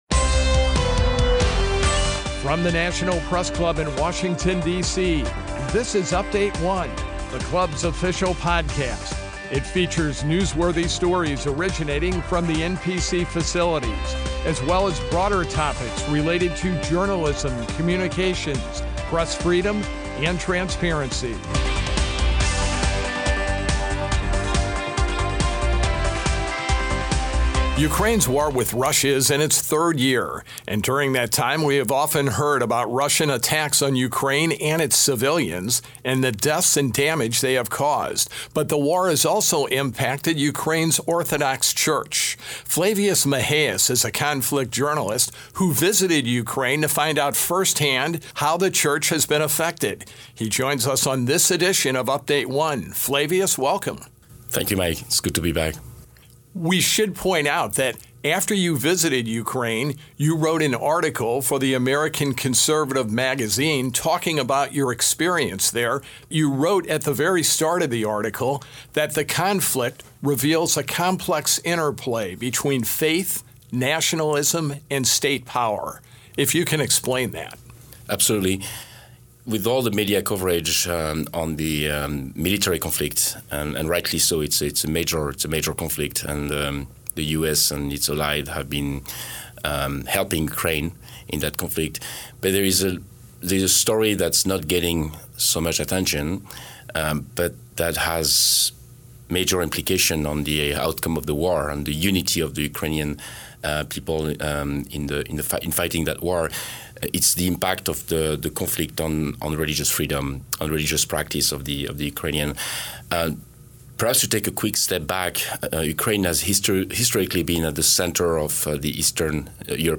Conflict Journalist Discusses the Impact of the Russia Ukraine War on Religious Freedom